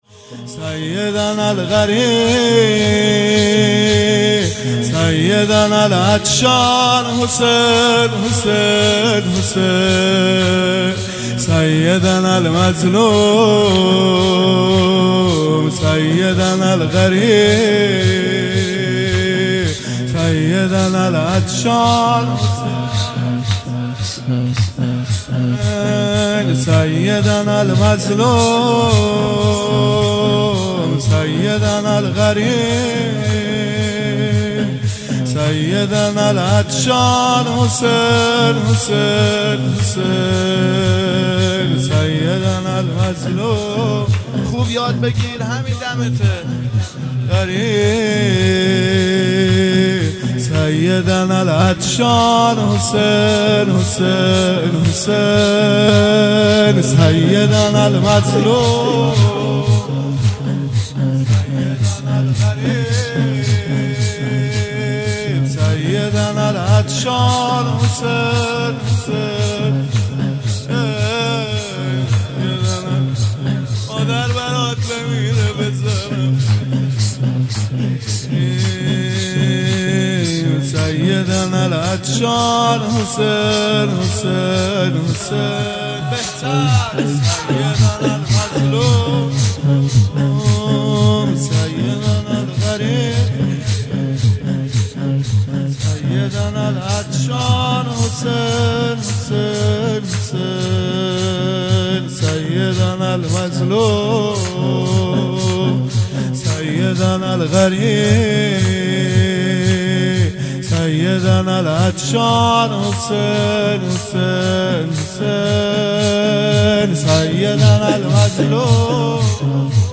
زمینه| سیدناالغریب
هیئت هفتگی